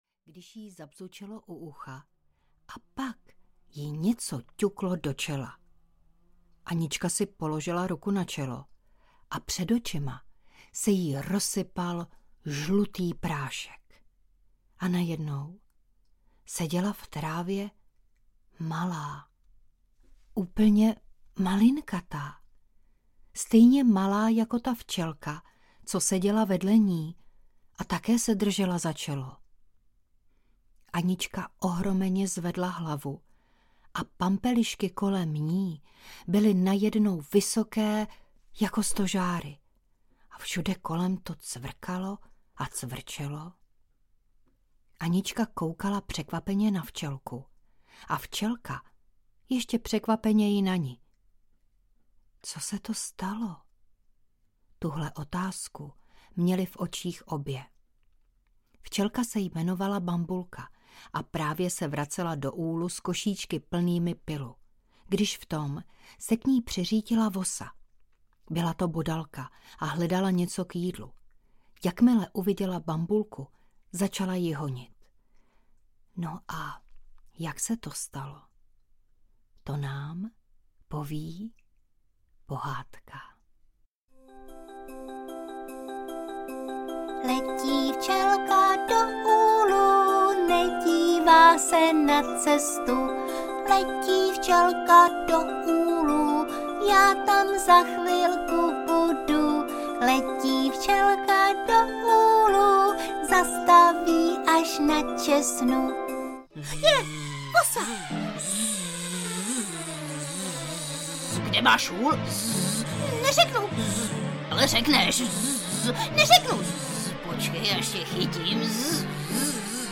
Včelka Bambulka audiokniha
Ukázka z knihy